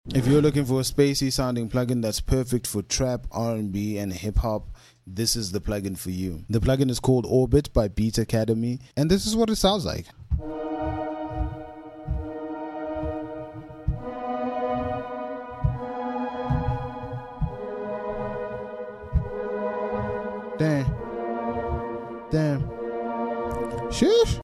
These are some Spacey vibes sound effects free download